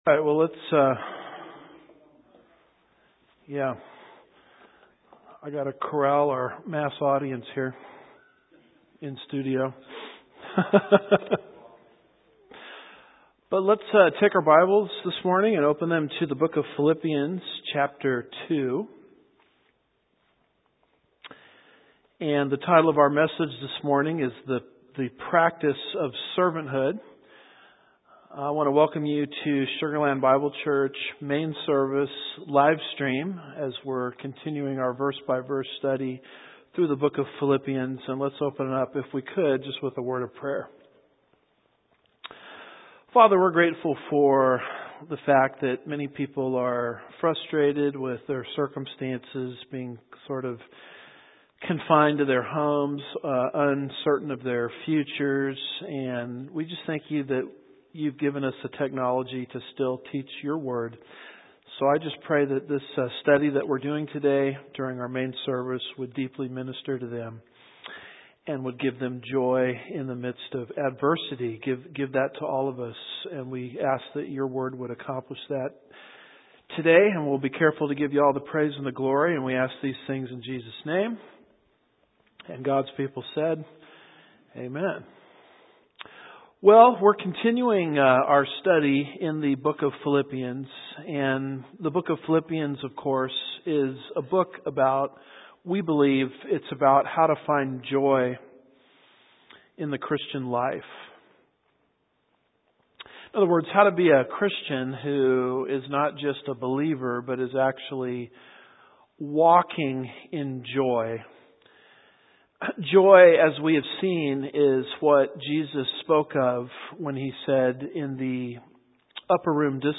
I want to welcome you to Sugar Land Bible Church, Main Service, Live Stream as we’re continuing our verse-by-verse study through the book of Philippians.